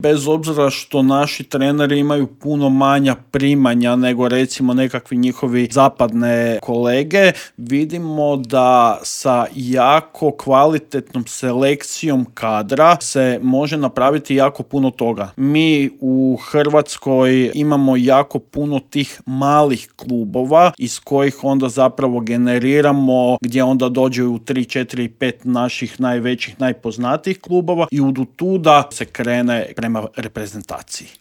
U intervjuu Media Servisa razgovarali smo o "maloj tvornici medaljaša"